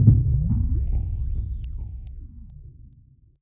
MB Sonic Boom.wav